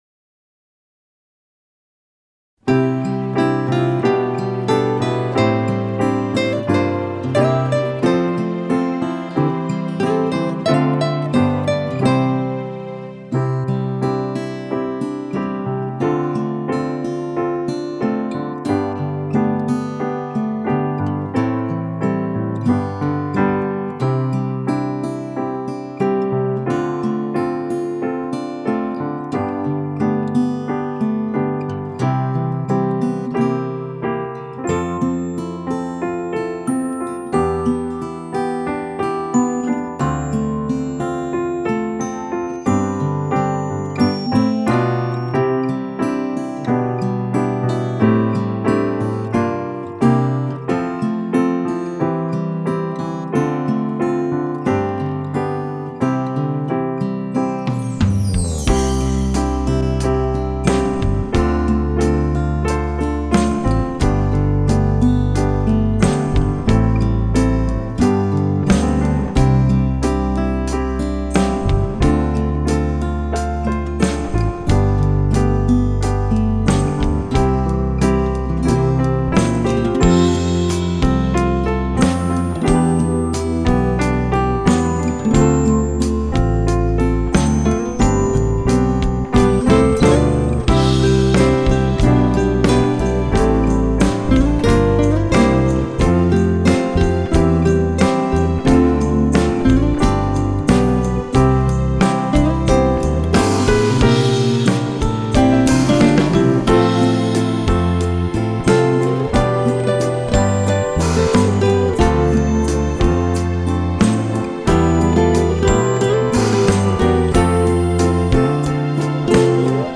カラオケ